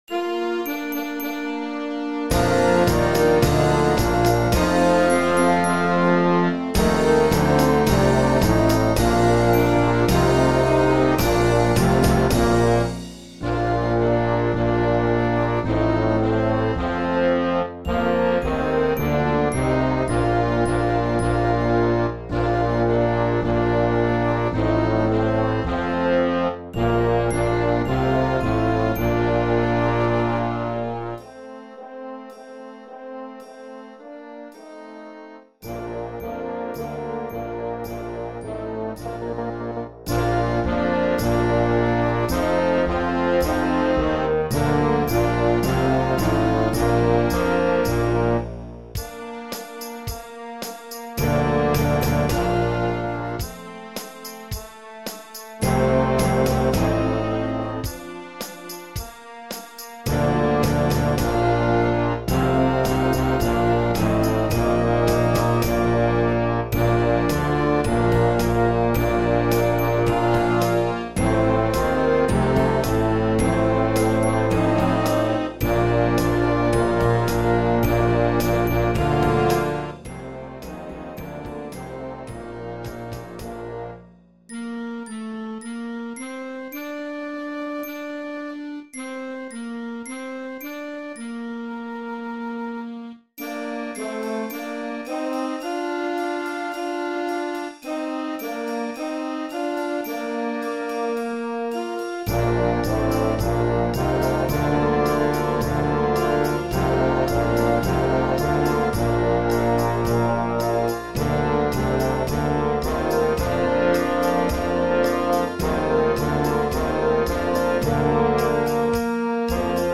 Beginner Concert Band